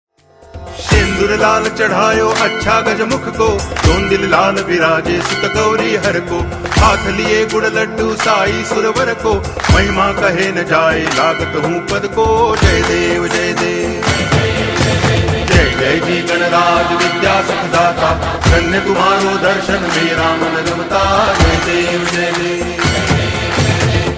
Tag: flute